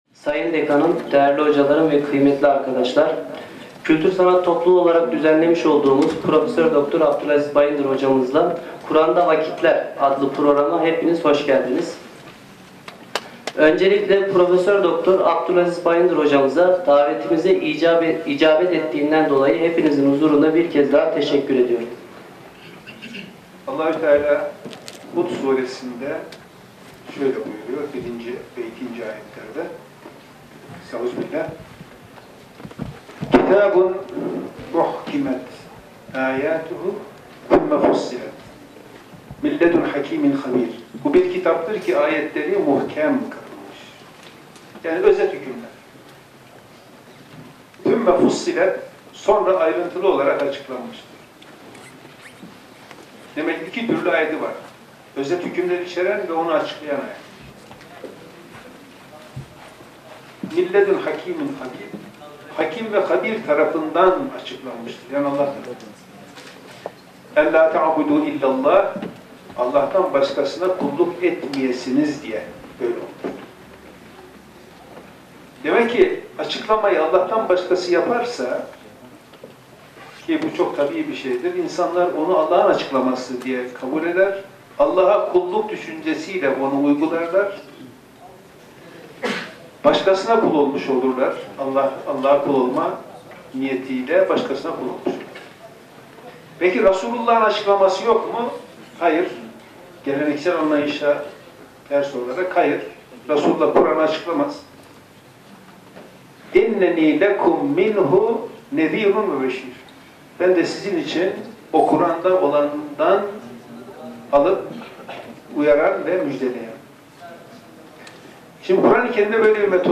Erzurum Konferansları – Atatürk Üniversitesi Konferansı